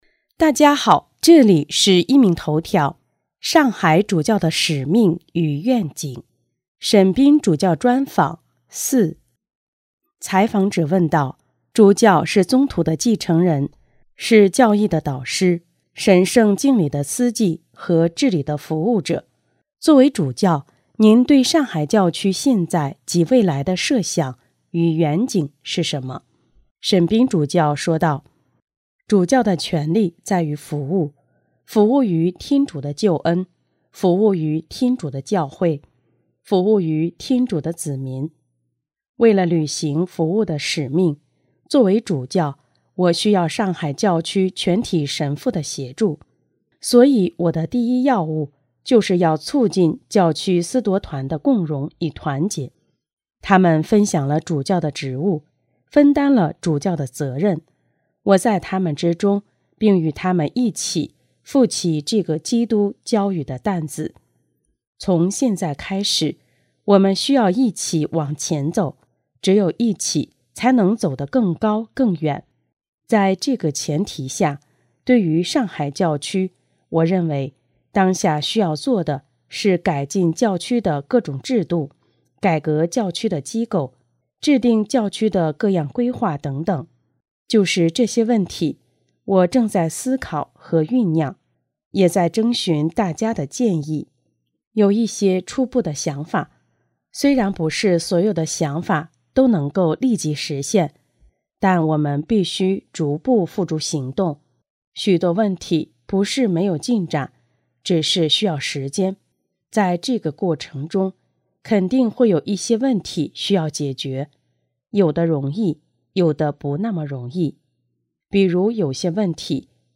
【壹明头条】| 沈斌主教专访(四):作为主教，您对上海教区现在及未来的设想与远景是什么？